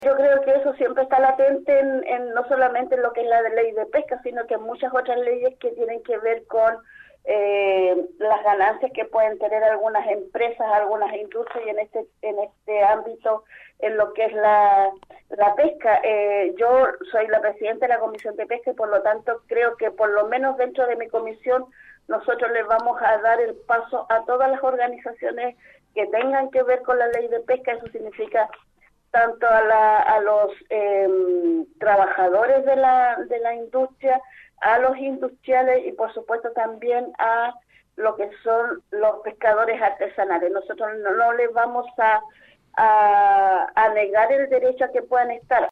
Al respecto, en Nuestra Pauta conversamos con la presidenta de la Comisión de Pesca de la Cámara, la diputada (PC) María Acevedo Sáez, quién recordó en primer lugar los delitos de soborno y cohecho relacionados a la tramitación de la ley en su momento.